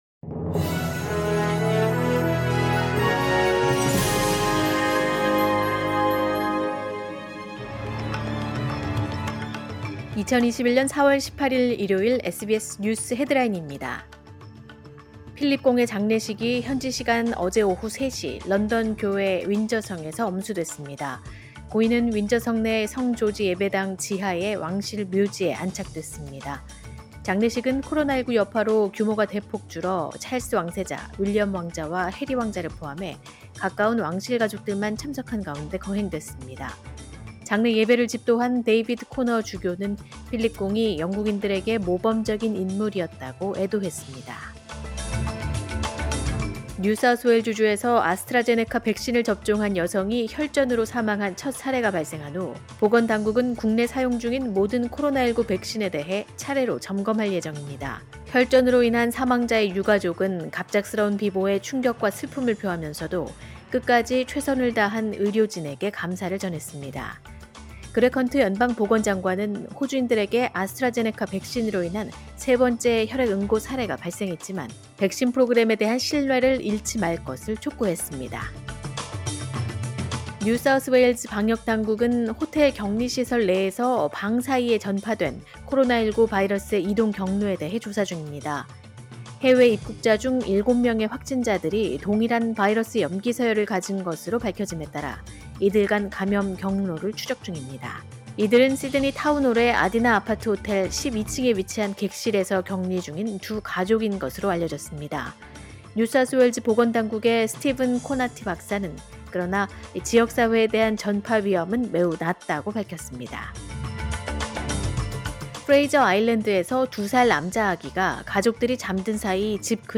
2021년 4월 18일 일요일 SBS 뉴스 헤드라인입니다.